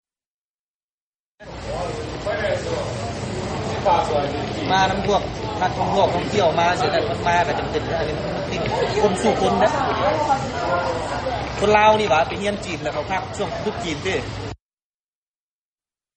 ສະເພາະແມ່ນພວກທີ່ຢູ່ໃນຂະແໜງການທ່ອງທ່ຽວ ຊຶ່ງໃນນັ້ນພະນັກງານນໍາທ່ຽວຄົນນຶ່ງ ທີ່ໄປຮັບເອົາແຂກທ່ອງທ່ຽວເດີນທາງມາສະໜາມບິນວັດໄຕ ກໍໃຫ້ຄໍາເຫັນວ່າ: